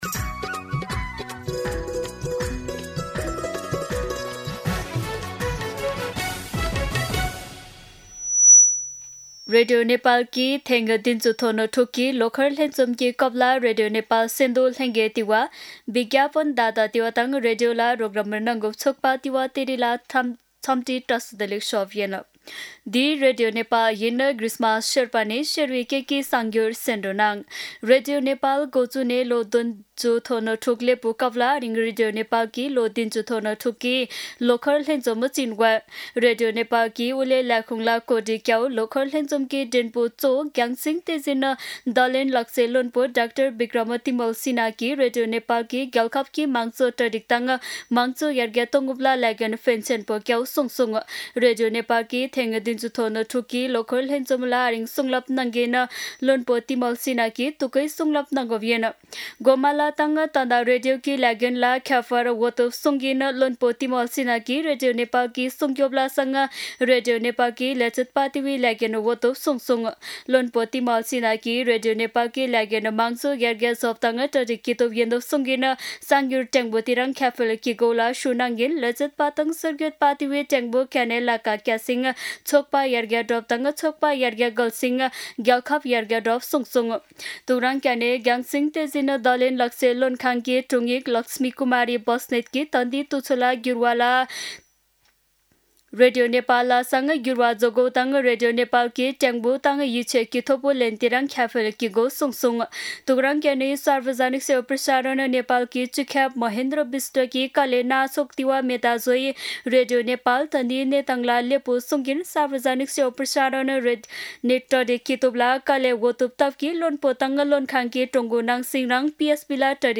शेर्पा भाषाको समाचार : २० चैत , २०८२
Sherpa-News-20.mp3